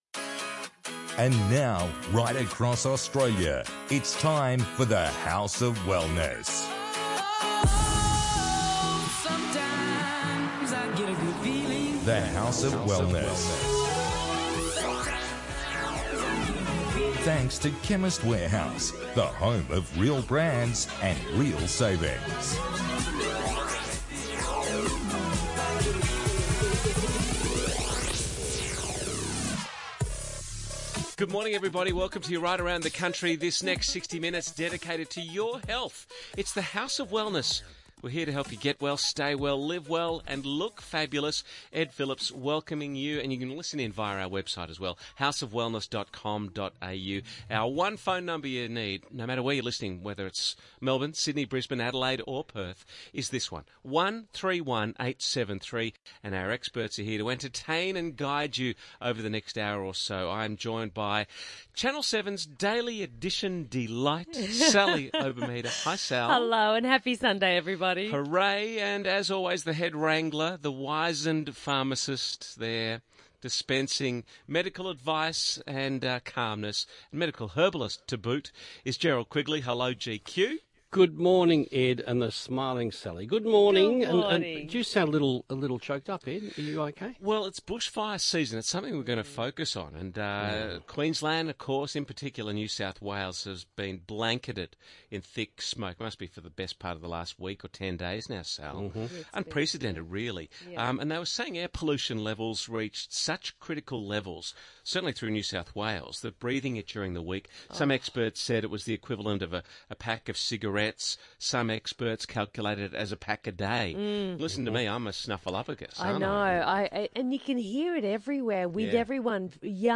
Get well, stay well, live well and look fabulous with The House of Wellness radio show.